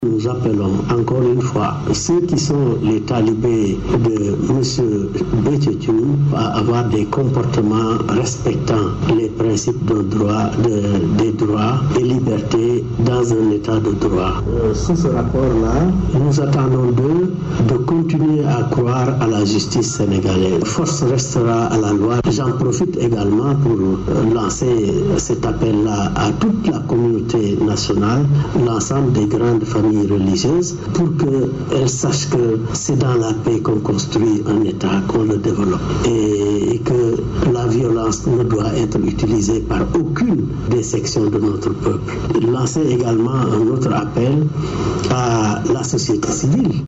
Au cours de cette manifestation, selon le ministre de l’Intérieur, on a enregistré 123 véhicules endommagés sans compter les autres édifices publics saccagés. Mbaye Ndiaye appelle au respect de l’Etat de droit. Il est interrogé par nos confrères de la Rfm.